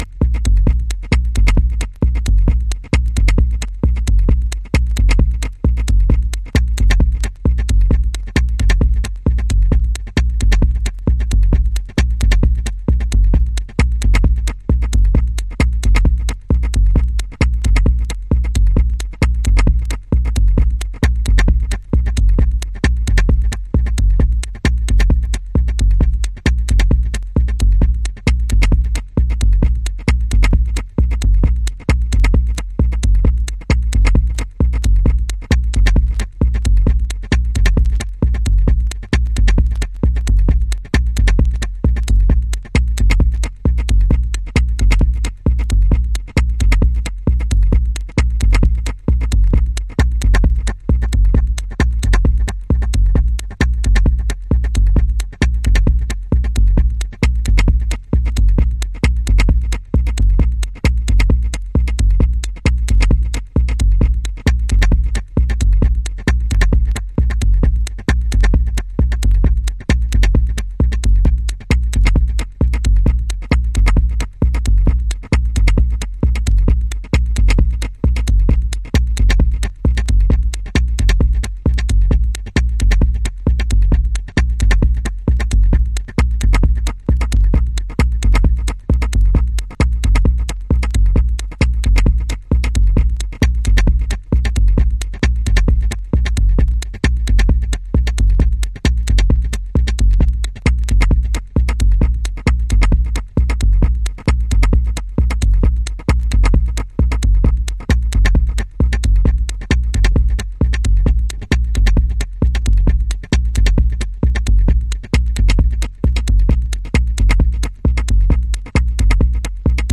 Early House / 90's Techno
装飾を一切省き骨組みだけで構成された彼岸の骨ミニマリズム